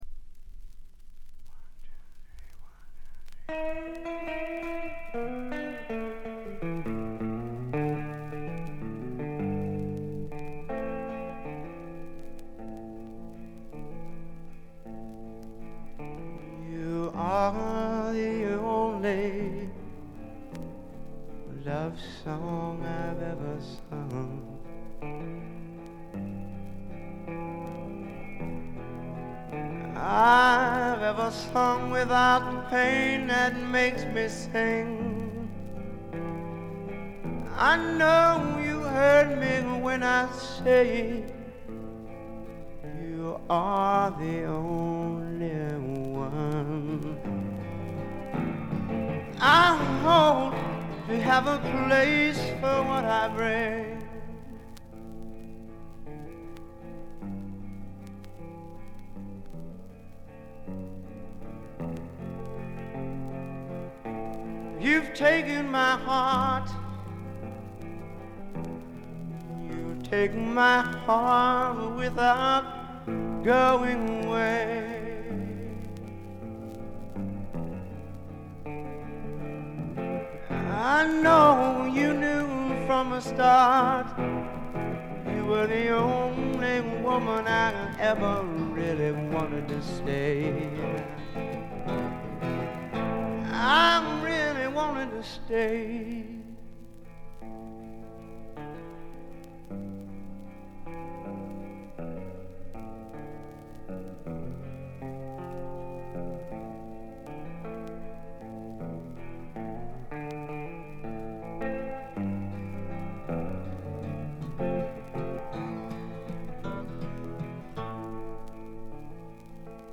バックグラウンドノイズ、チリプチ多め大きめですが凶悪なものや周回ノイズはありません。
試聴曲は現品からの取り込み音源です。
Guitar
Drums